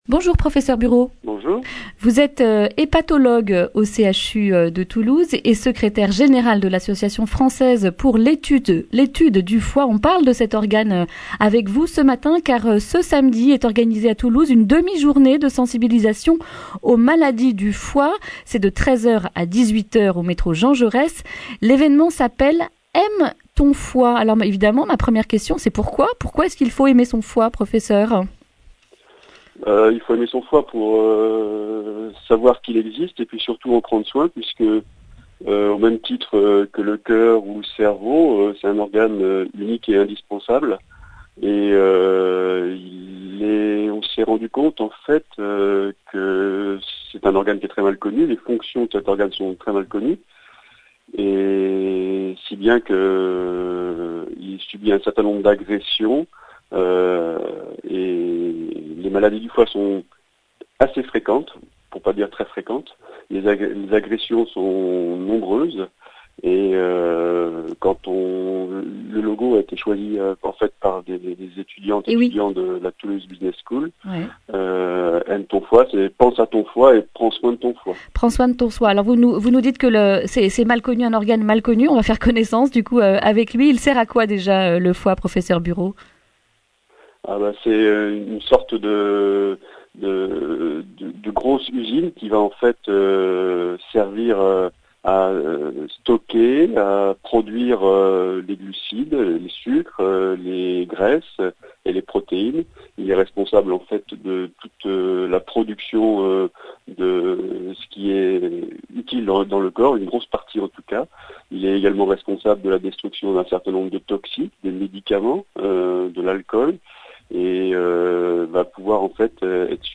vendredi 13 septembre 2019 Le grand entretien Durée 10 min
Journaliste